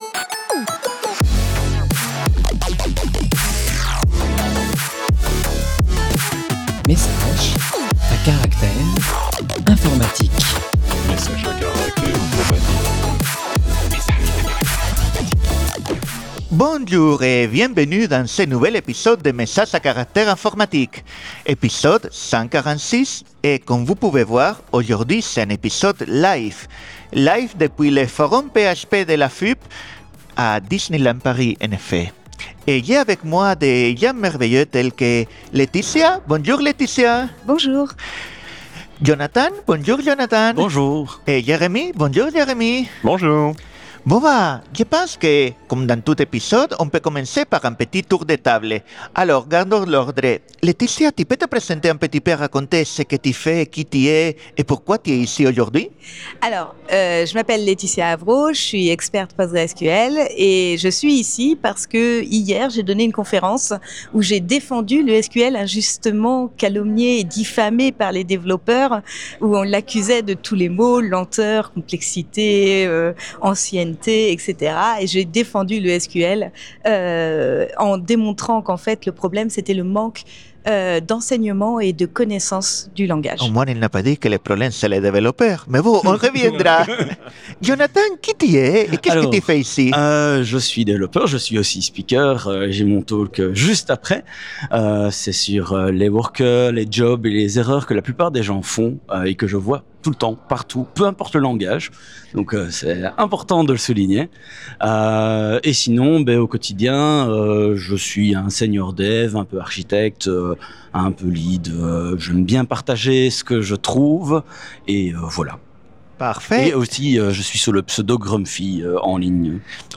MACI #146 explore PostgreSQL 18, le SQL, la chute des contributions Open Source et le DevOps en live depuis le Forum PHP AFUP.
Dans cet épisode enregistré durant le forum PHP de l'AFUP à Disneyland Paris, nous parlons SQL, nous faisons un focus sur quelques nouveautés apportées par PostgresSQL 18, nous parlons de la baisse sensible et générale du nombre de contributeurs aux projets Open Source, d'Incus un moteur de conteneurs et de VM avant de nous demander comment ça se passe dans la vraie vie entre Devs et Ops... Sans oublier de finir en musique.